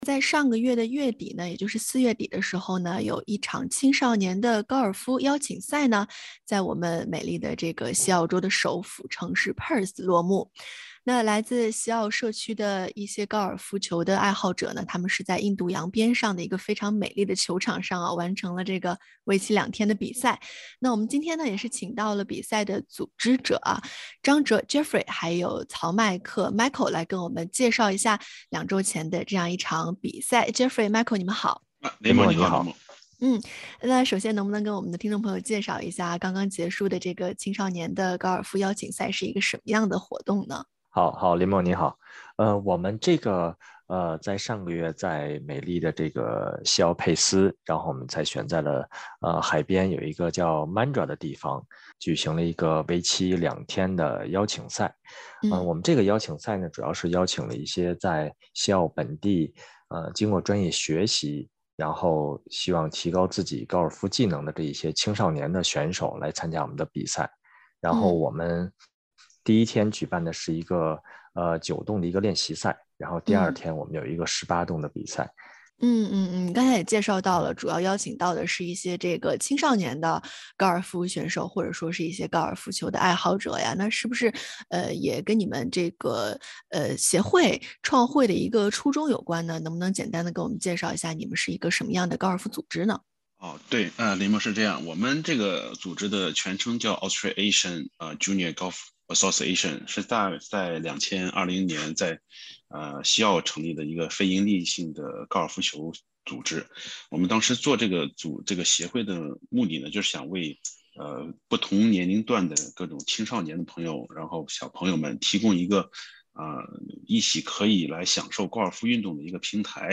（欢迎您点击封面图片，收听完整采访） （本文系SBS原创内容，未经许可，不得转载。